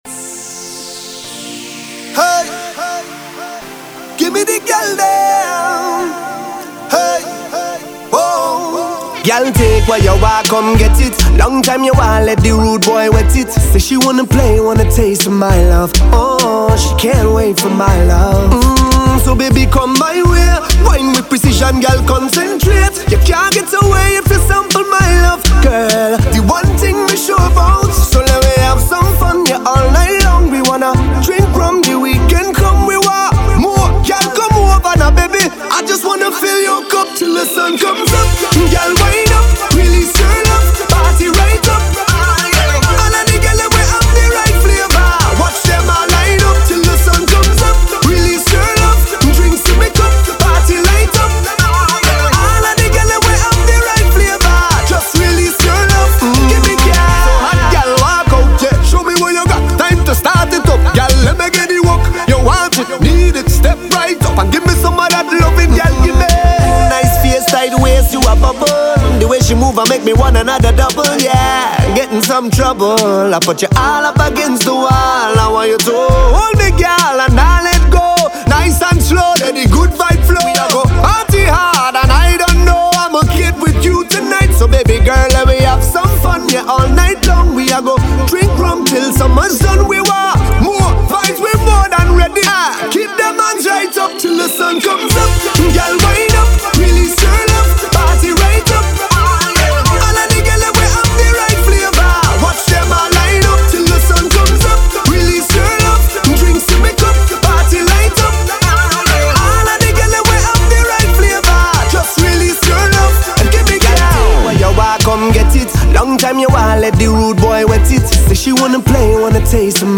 ok, kinda cheesy, but I love it anyway